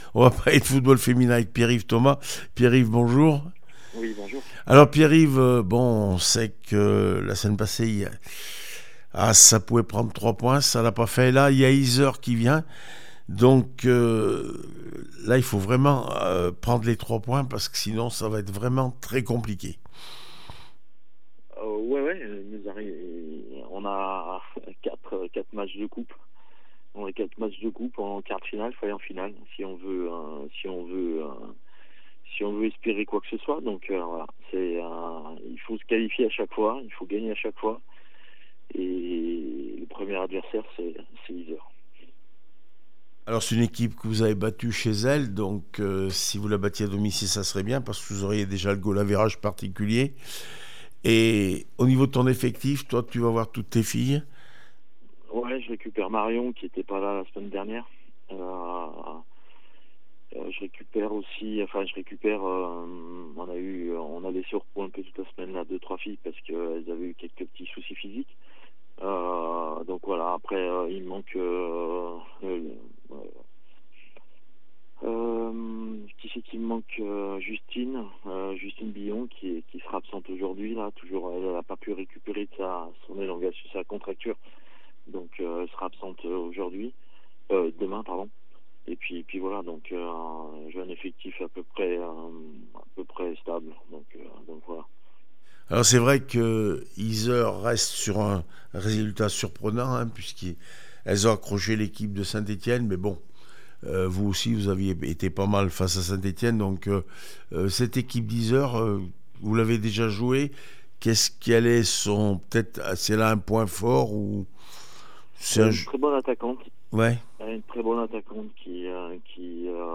1 avril 2023   1 - Sport, 1 - Vos interviews, 2 - Infos en Bref